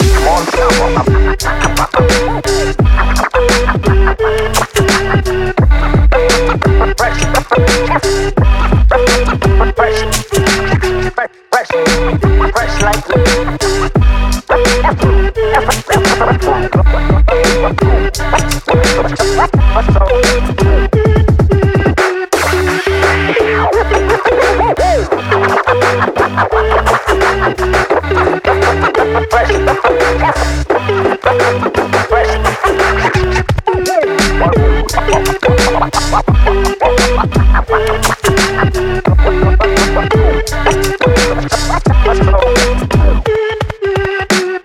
BPM86、Em/Gmaj、同じドラムセット縛りの6パターンのビート・トラックです。
EMO HIPHOP LOOP TRACK BPM86 Em/Gmaj pattern C